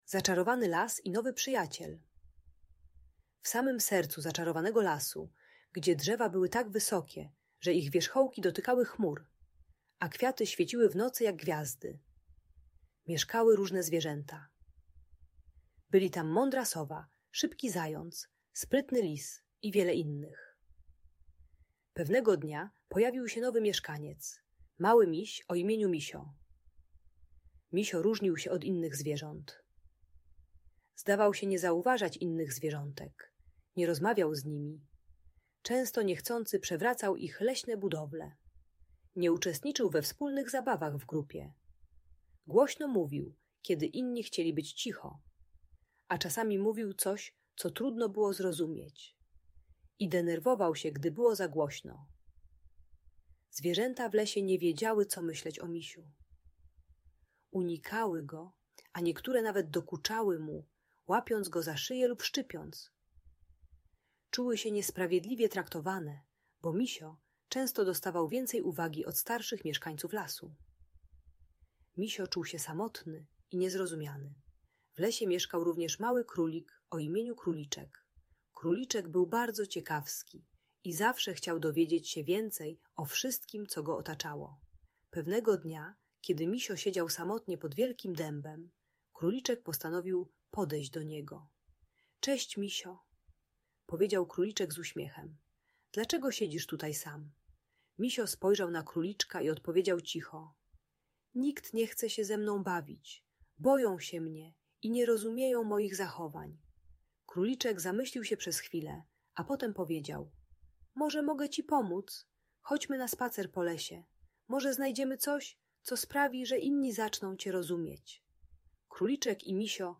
Zaczarowany Las i Nowy Przyjaciel - Audiobajka